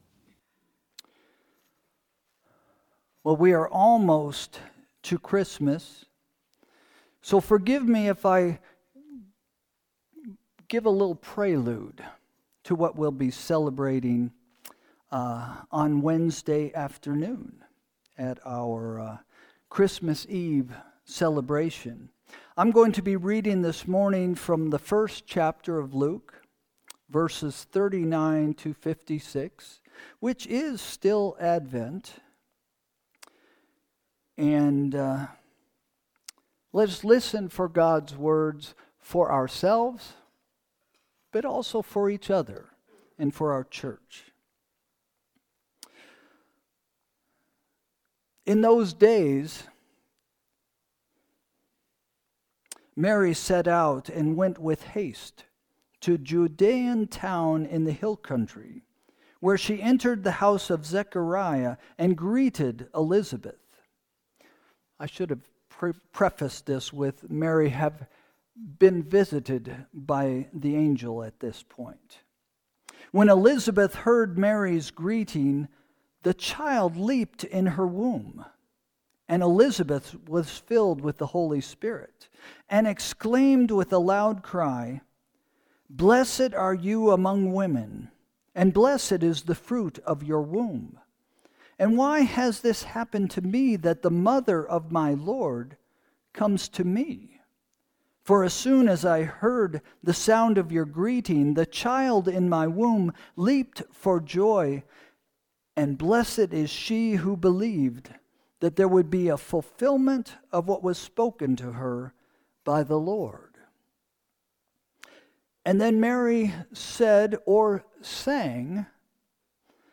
Sermon – December 21, 2025 – “From The Bottom”